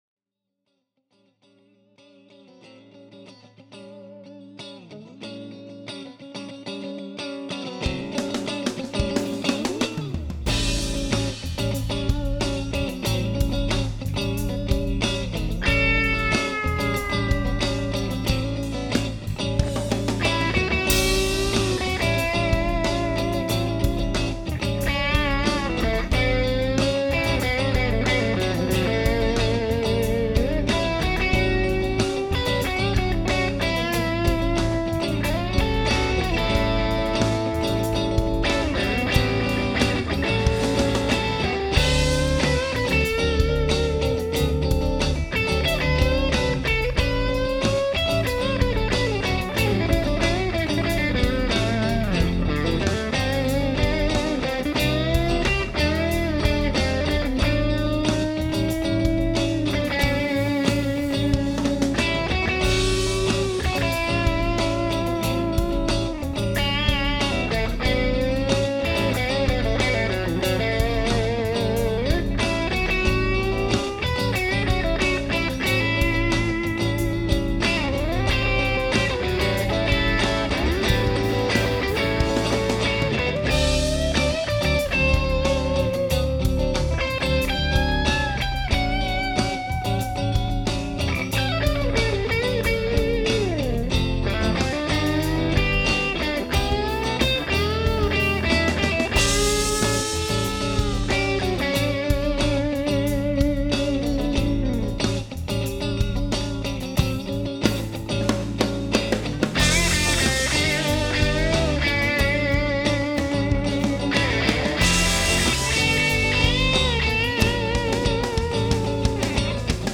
Guitars: Rhythm – Strat, Lead/Melody – Saint Guitars Messenger (Goldie)
Amps: Rhythm – Aracom VRX22, Lead/Melody – Aracom PLX18 BB Trem
Guitars were recorded at conversation levels using the Aracom PRX150-Pro attenuator.
Effects: KASHA Overdrive, GeekMacDaddy Geek Driver
Like many of my instrumentals, it started out as a backing track that I could practice over.
This resulted in a bit brighter, but fatter tone.
Finally, the cool thing was that I recorded the song in two takes.